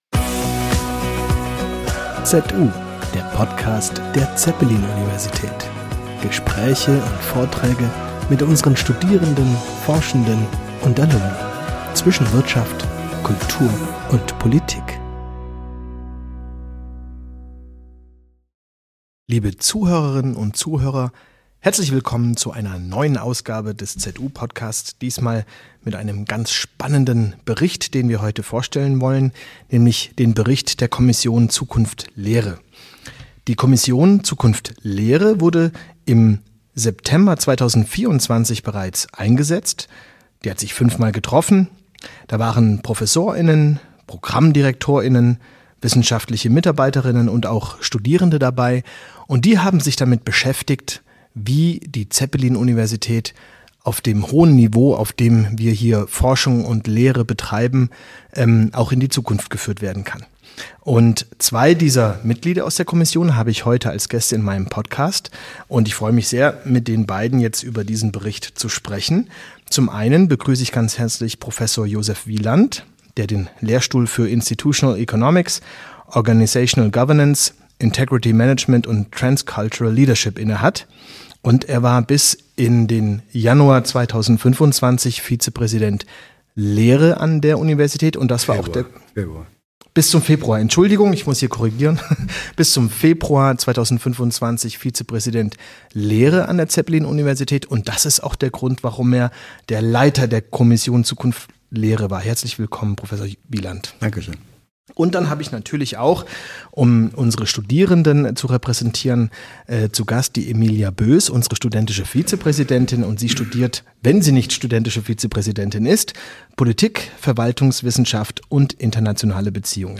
Talk ~ ZU - Der Podcast der Zeppelin Universität Podcast